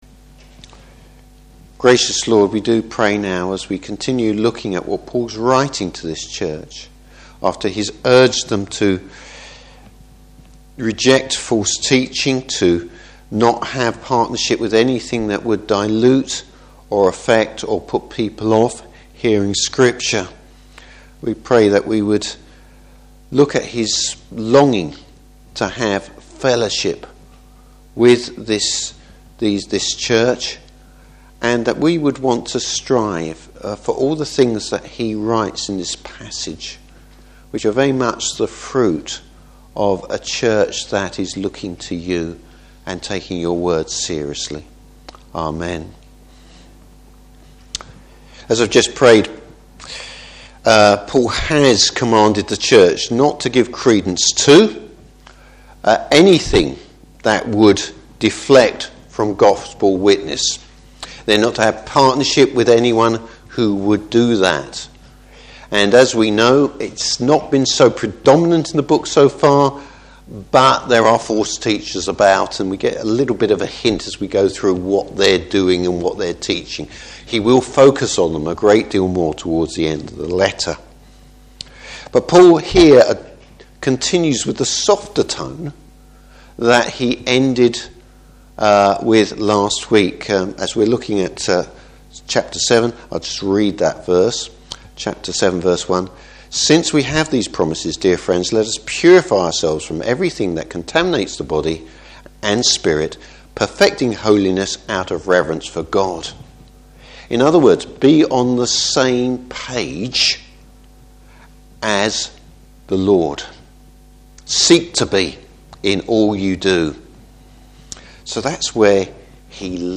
Service Type: Morning Service The fellowship of the Church.